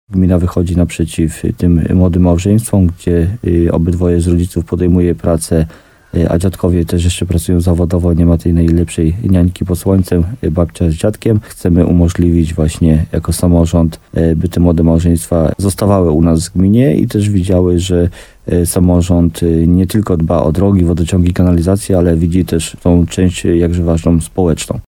Chcemy umożliwić opiekę, by te młode małżeństwa zostawały w gminie i widziały, że jako samorząd dbamy nie tylko o drogi, kanalizację, ale widzimy te bardzo ważną część społeczną – mówi wójt Jacek Migacz.